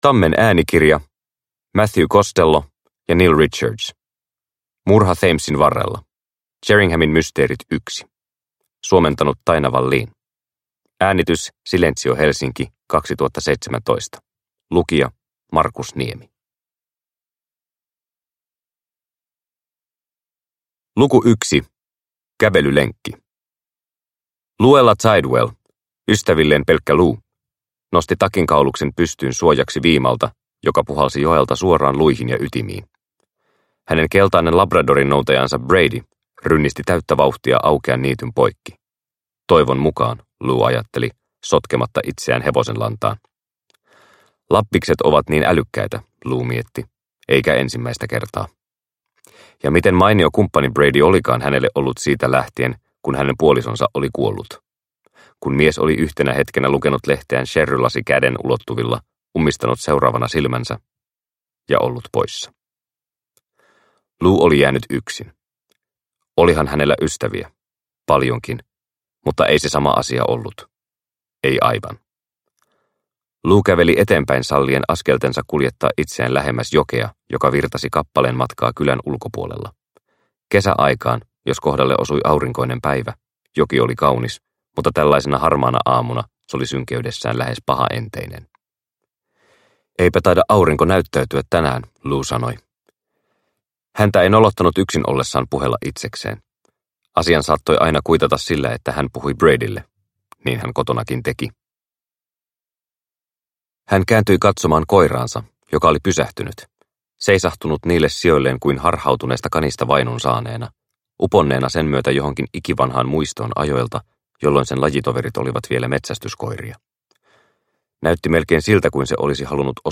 Murha Thamesin varrella – Ljudbok – Laddas ner